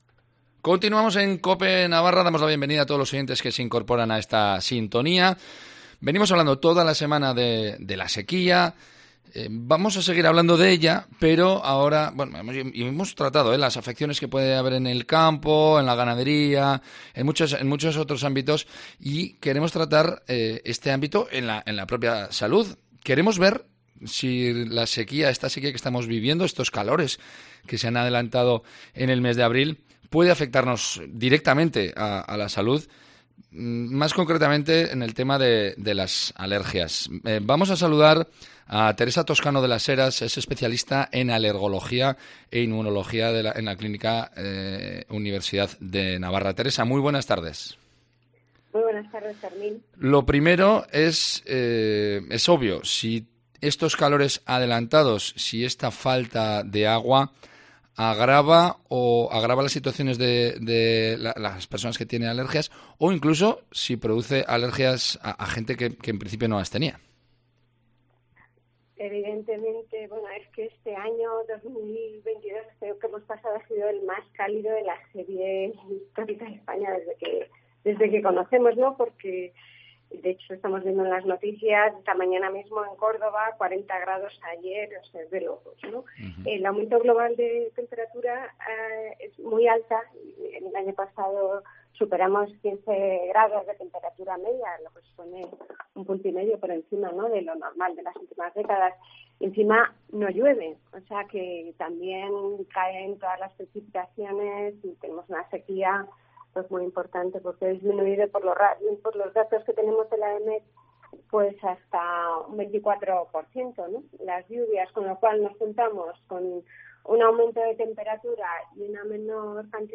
ha pasado por los micrófonos de Cope Navarra para conocer un poco más cómo influyen estos calores intensos y la sequía en las alergias. Ha asegurado que el cambio climático influye, y mucho, en las alergias. Éstas cada vez afectan a más personas, pueden ser más severas y se prolongan más en el tiempo.